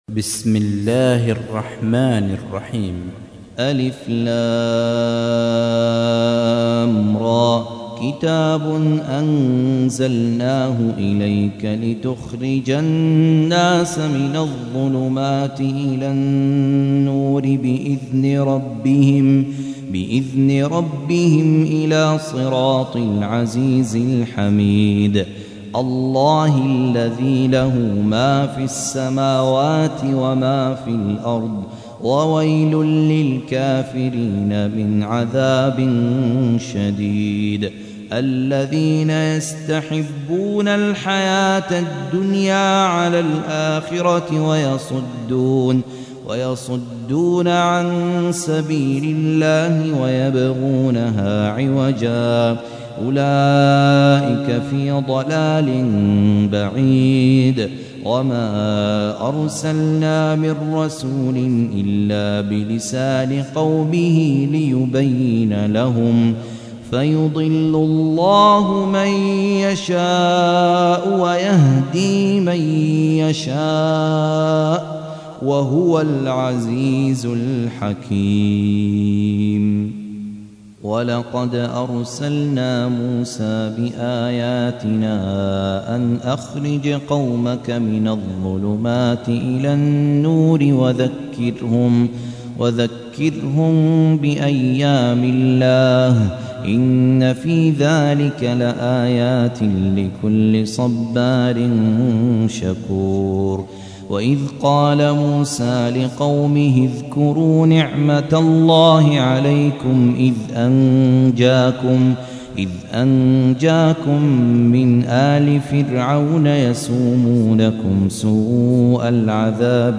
تحميل : 14. سورة إبراهيم / القارئ خالد عبد الكافي / القرآن الكريم / موقع يا حسين